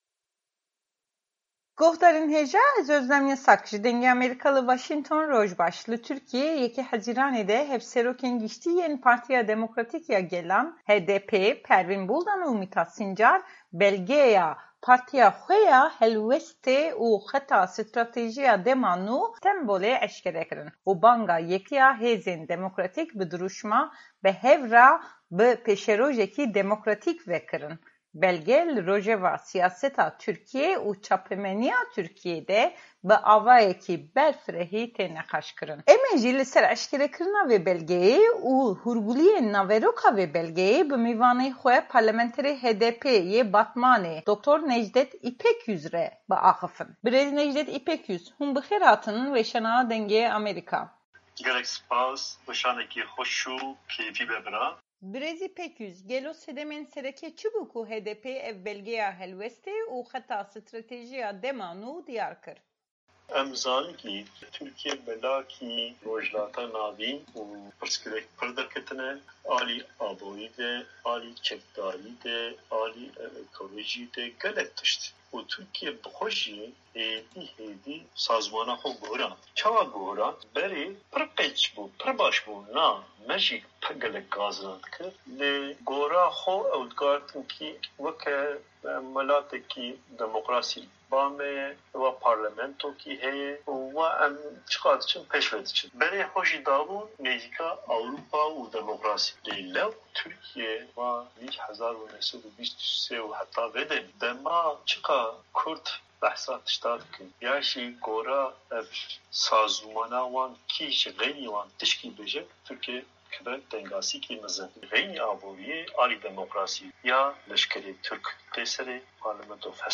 Heypeyvîna Parlementera HDP'ê ya Batmanê Nejdet Îpekyüz